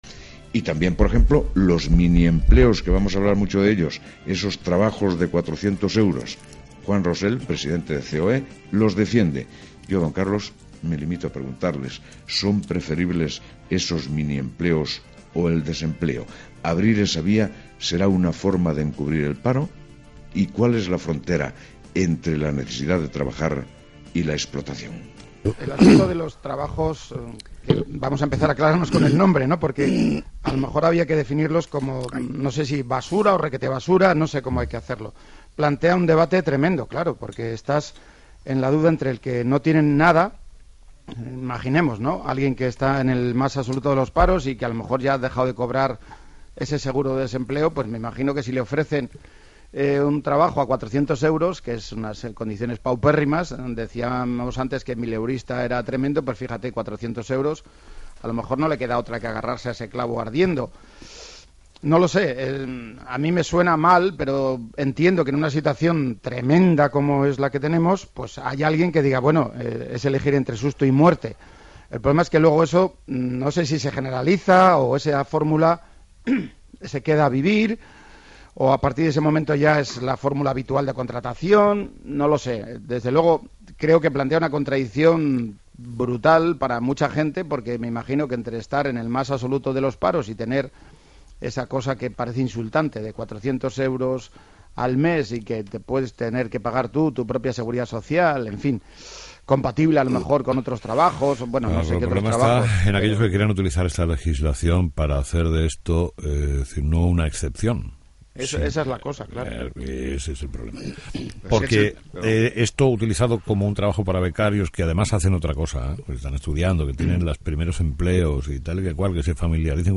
Entrevistado: "Juan Rosell"